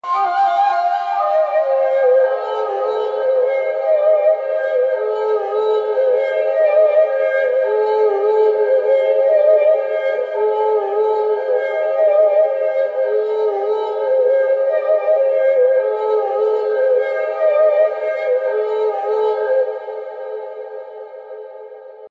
Vorgelesen von echten Menschen – nicht von Computern.